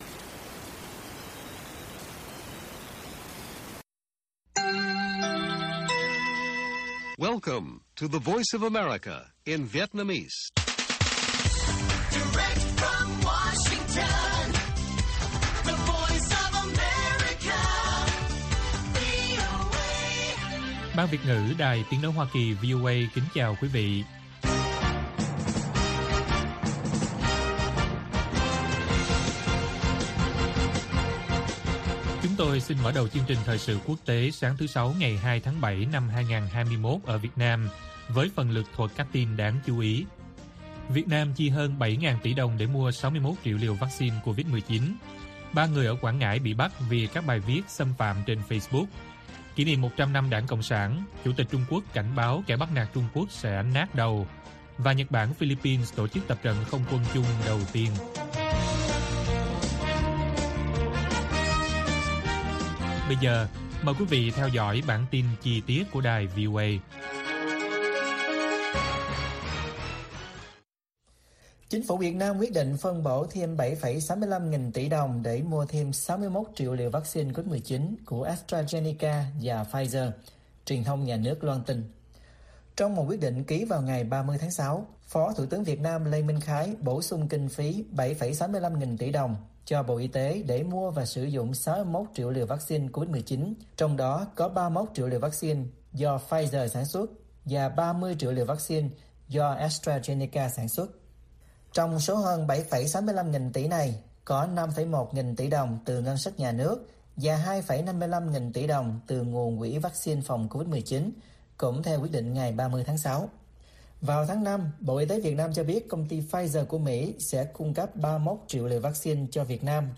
Bản tin VOA ngày 2/7/2021